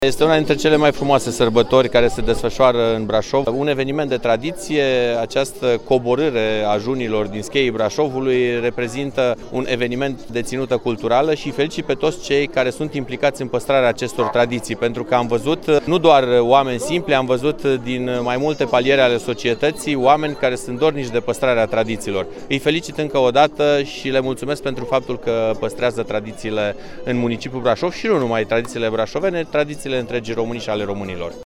Ministrul Culturii, Daniel Breaz a fost prezent ieri la parada Junilor brașoveni, cu ocazia împlinirii a o sută de ani de la prima Zi de Călări din duminica Tomii.
Ministrul Daniel Breaz a ținut să sublinieze importanța evenimentului: